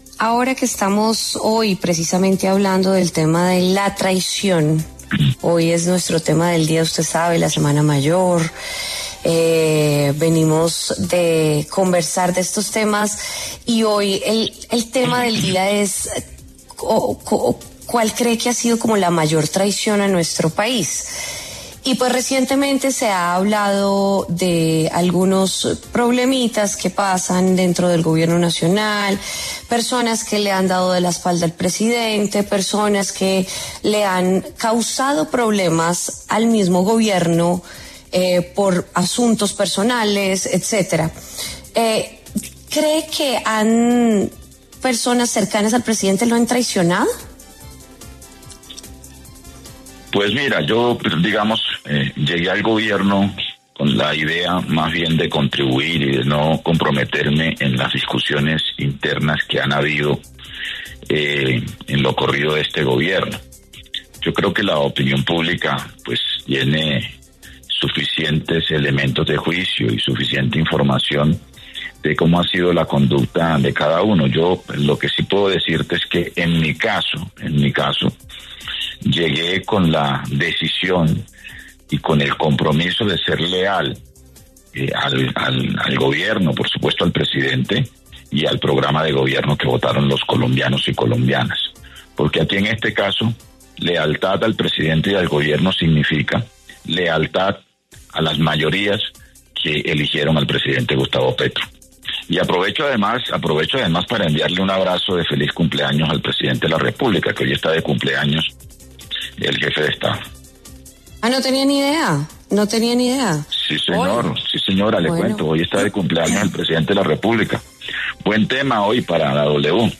En diálogo con W Fin De Semana, Antonio Sanguino, ministro de Trabajo, se refirió a la confrontación y los problemas que tienen la canciller Laura Sarabia y el ministro del Interior, Armando Benedetti, algo que, según él, “le hace daño al presidente” de la República, Gustavo Petro.